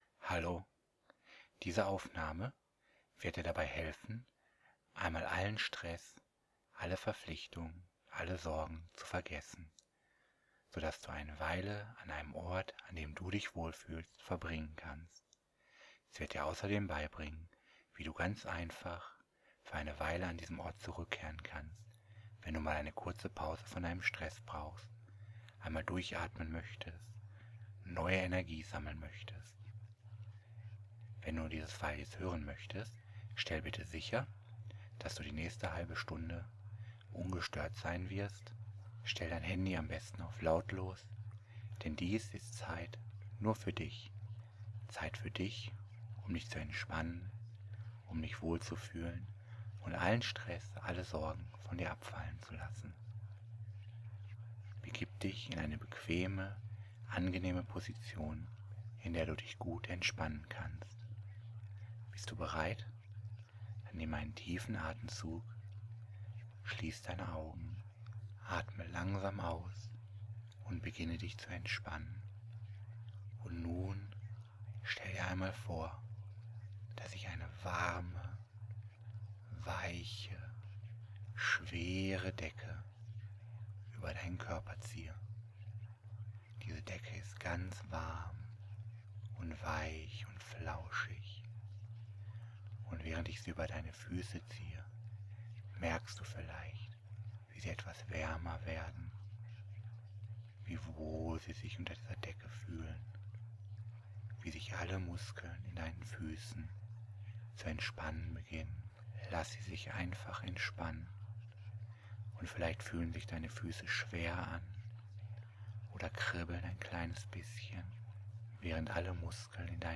Diese Aufnahme ist ein Enspannungs und Anti Stress Hypnose.